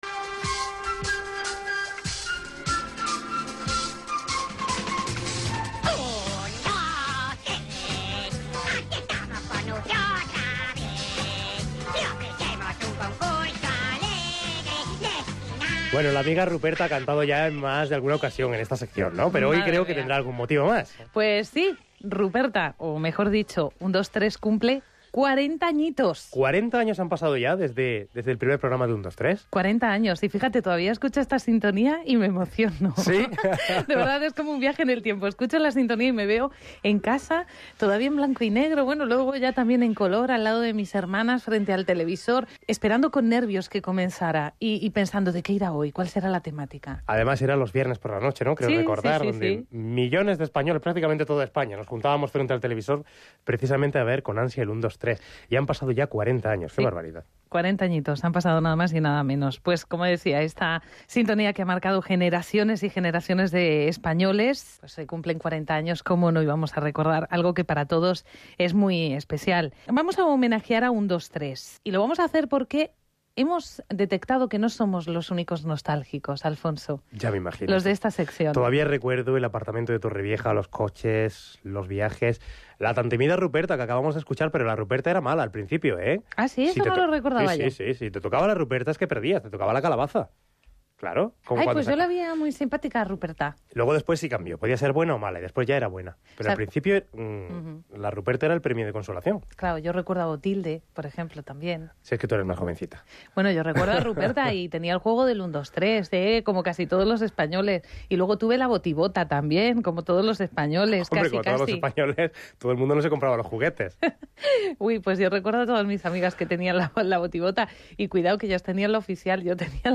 Primera parte de la charla